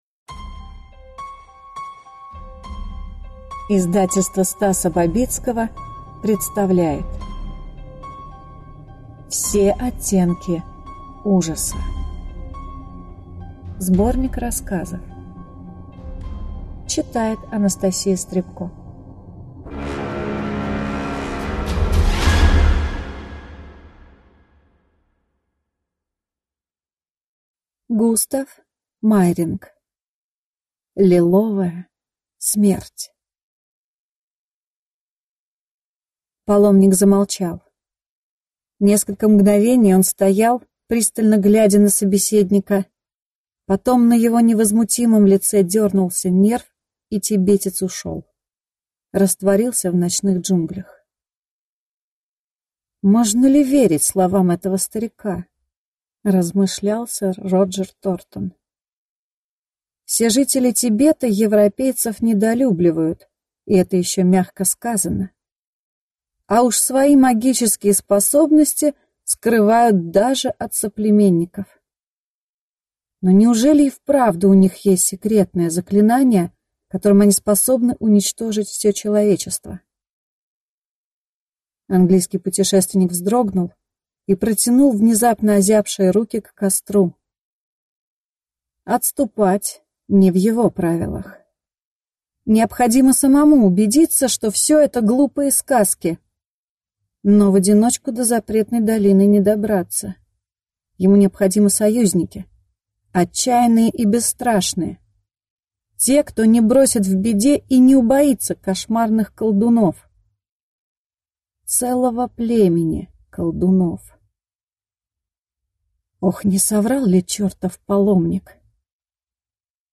Аудиокнига Все оттенки ужаса | Библиотека аудиокниг